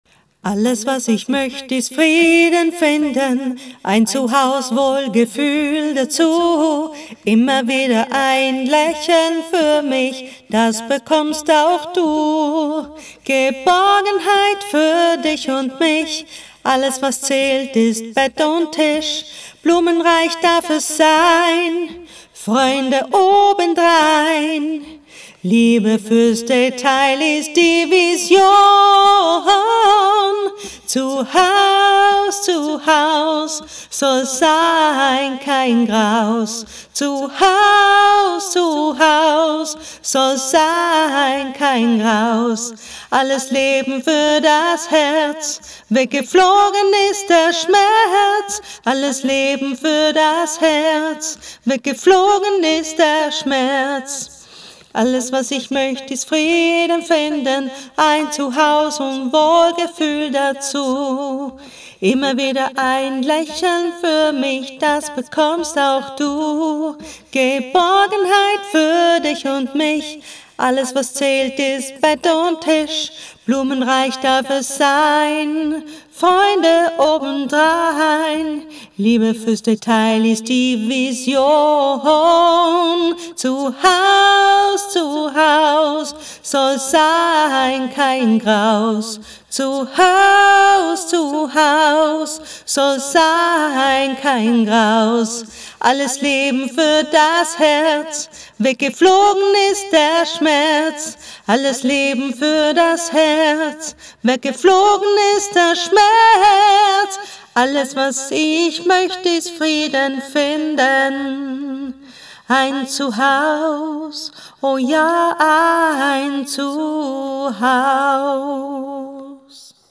Die Hörbeiträge aus dem Tram
Accapella ZuHause